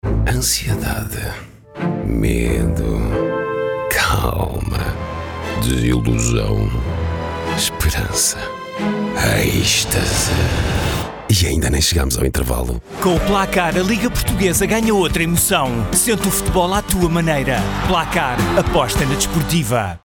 spot de rádio da campanha.